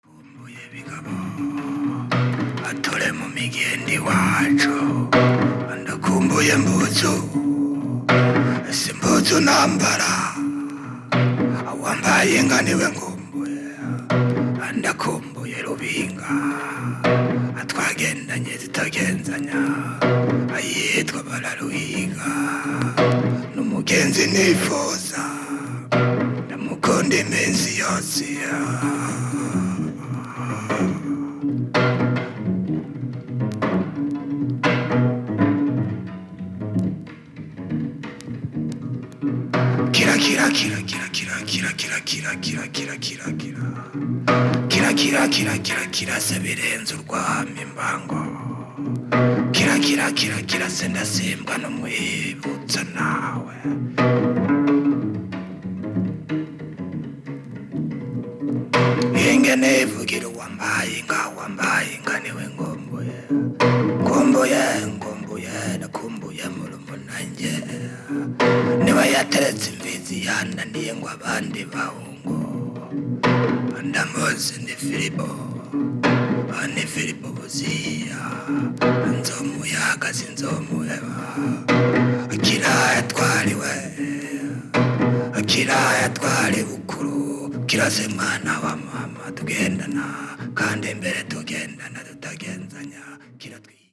"Chant Avec Cithare"
Title : Musiques Traditionnelles